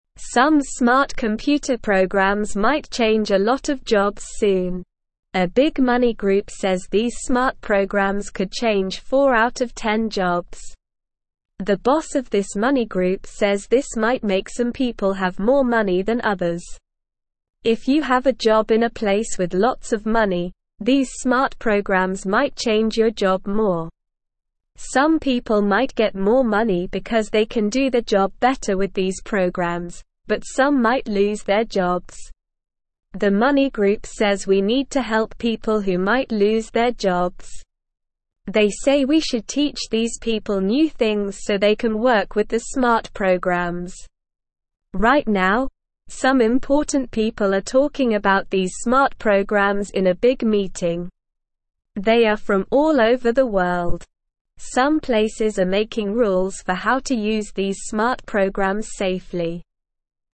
Slow
English-Newsroom-Beginner-SLOW-Reading-Smart-computer-programs-could-change-many-jobs.mp3